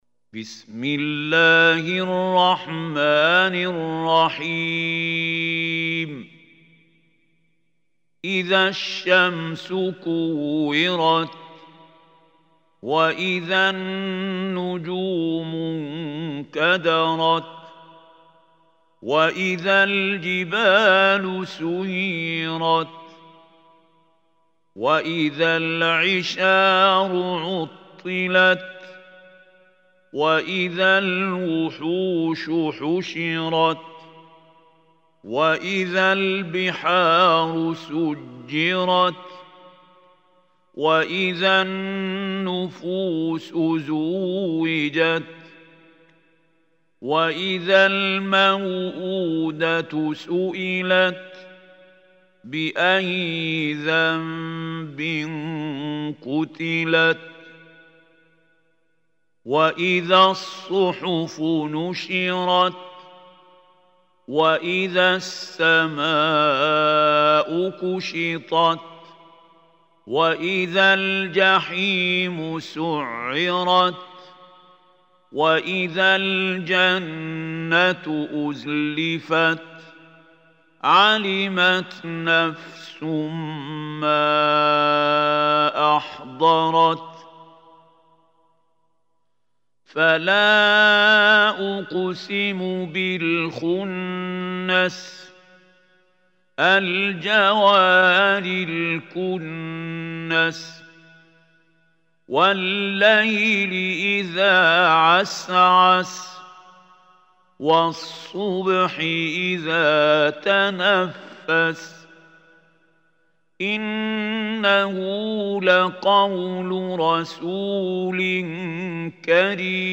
Surah Takwir Recitation by Mahmoud Khalil Hussary
Surah At-Takwir is 81 surah of holy quran. Listen or play online mp3 tilawat / recitation in Arabic in the beautiful voice of Sheikh Mahmoud Khalil Hussary.